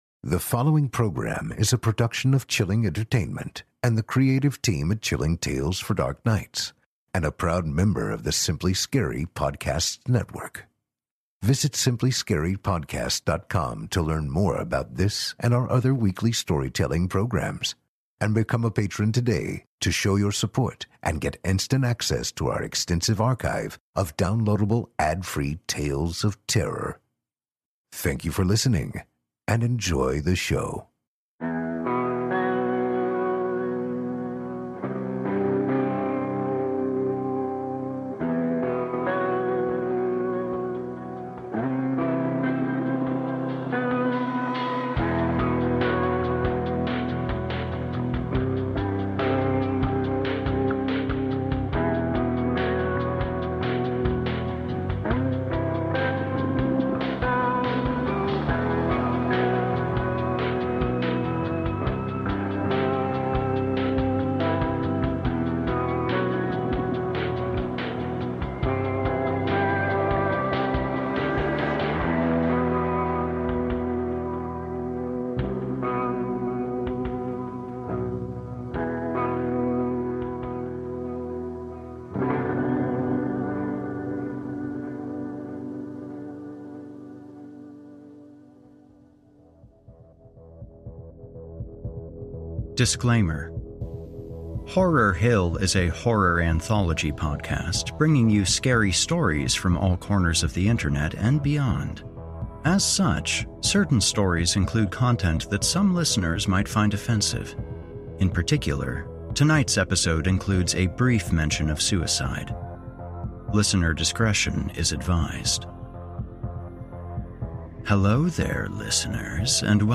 and tonight’s episode is a double-feature of scary stories